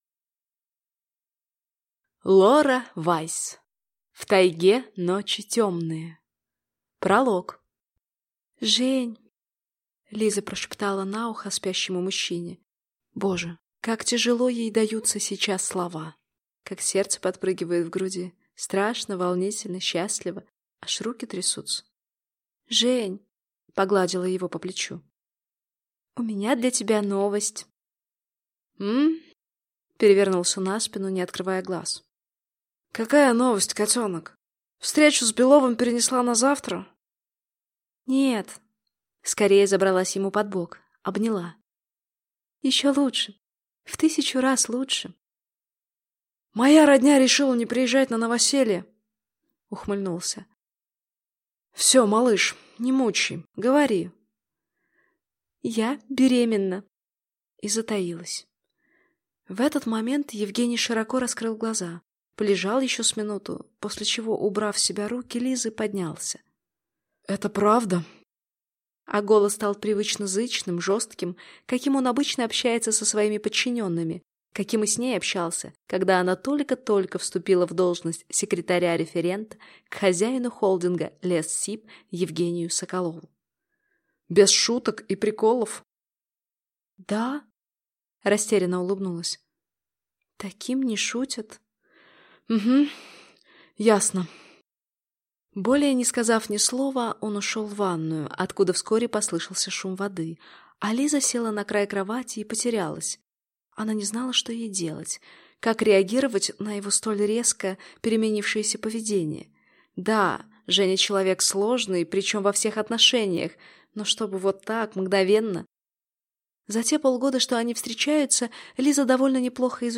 Аудиокнига В тайге ночи тёмные | Библиотека аудиокниг